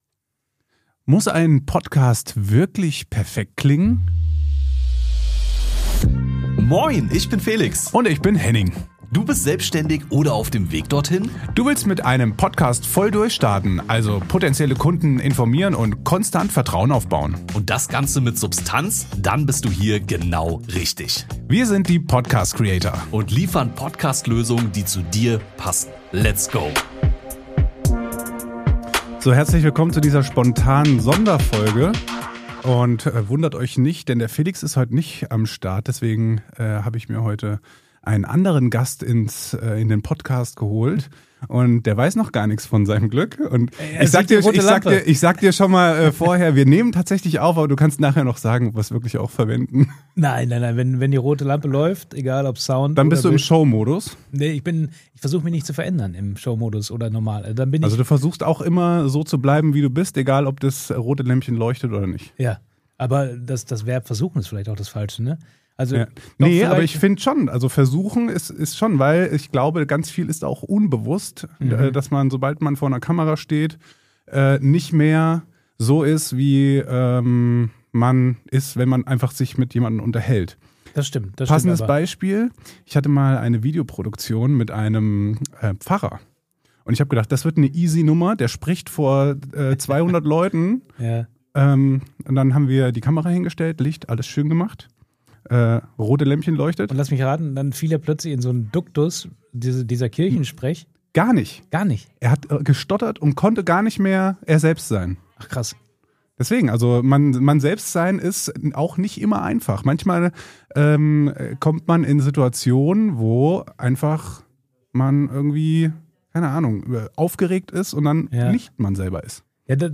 Diese Folge ist spontan aus dem Studio entstanden und dreht sich genau um die Frage, die sich viele Selbstständige, Creator und Unternehmer stellen: Muss ein Podcast wirklich perfekt sein, um ernst genommen zu werden?